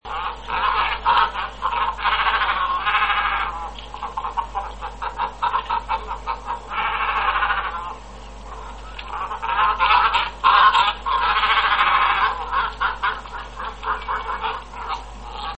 Kormoran czarny - Phalacrocorax carbo
głosy